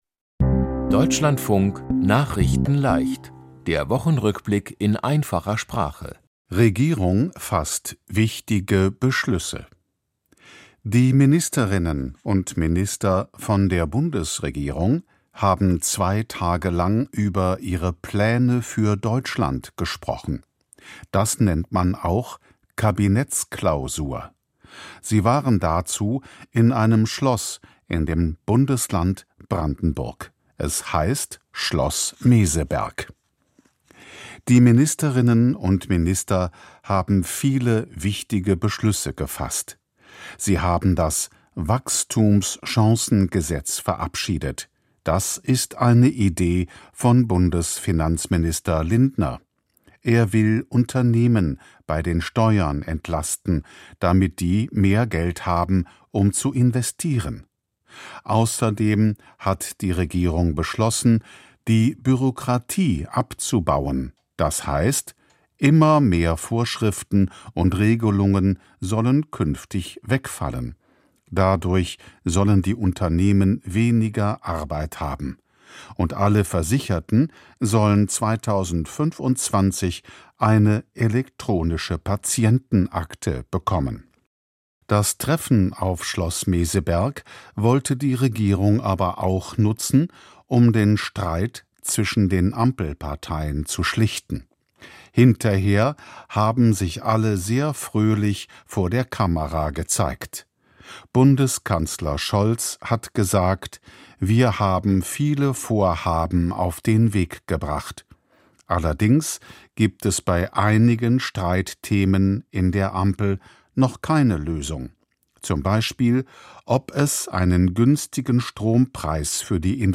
Die Themen diese Woche: Regierung fasst wichtige Beschlüsse, Affäre um anti-semitisches Flug-Blatt, Polizei-Foto von Trump, Verseuchtes Wasser von Atom-Kraft-Werk ins Meer geleitet, Taylor Swift schafft neuen Rekord, Skandal um Kuss bei Fussball-WM. nachrichtenleicht - der Wochenrückblick in einfacher Sprache.